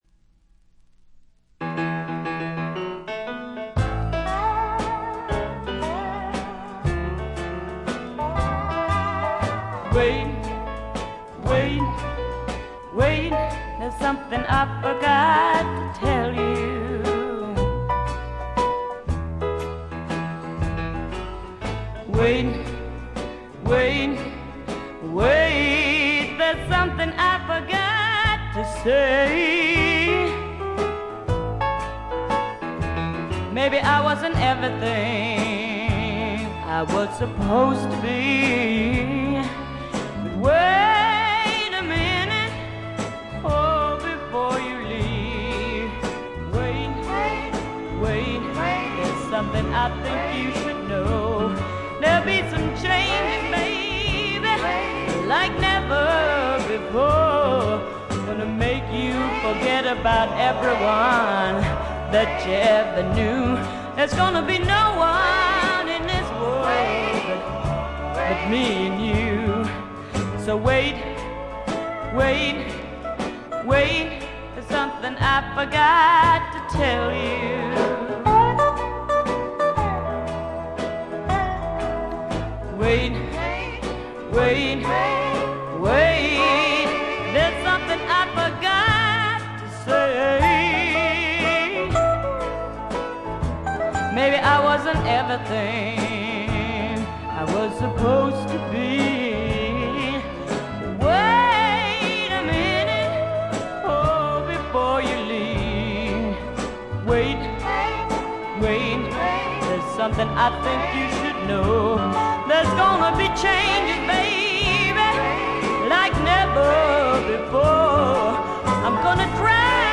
部分試聴ですが、軽微なチリプチ、バックグラウンドノイズ程度。
試聴曲は現品からの取り込み音源です。